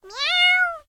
cat_meow_normal4.ogg